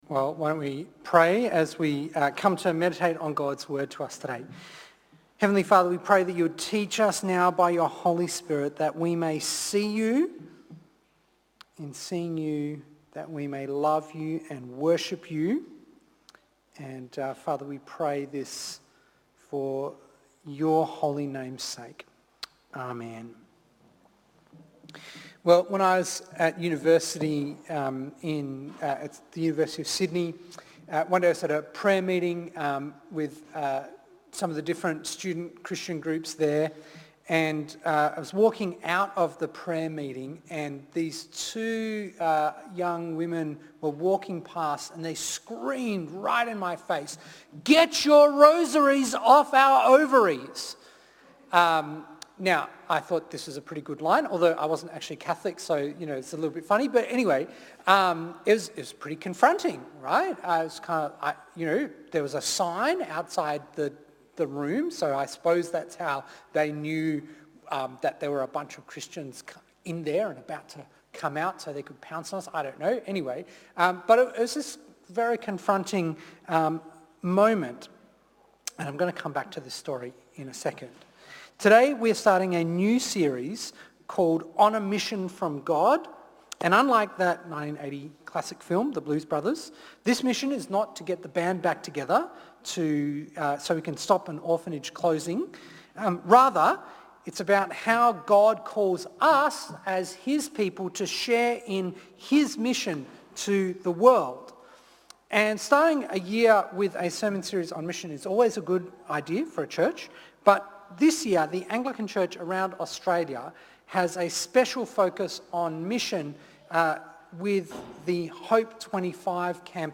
One Mission Preacher